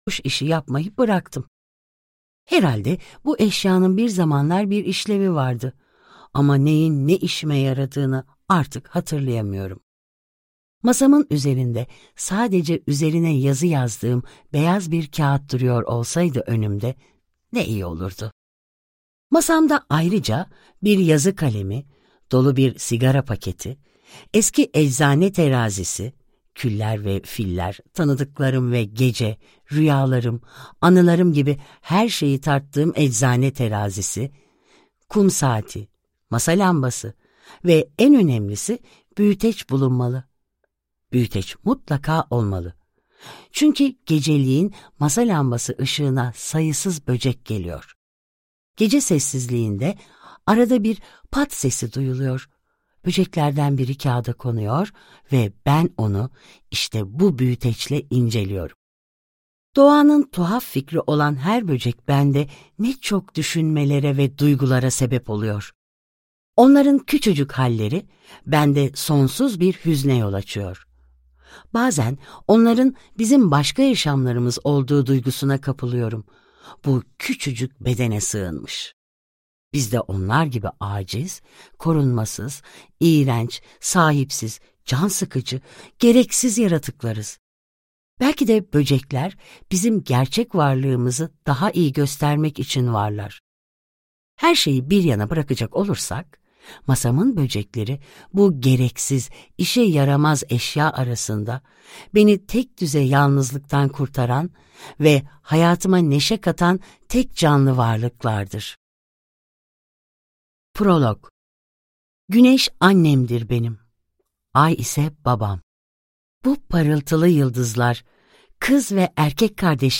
Sivrisinek Şehirde - Seslenen Kitap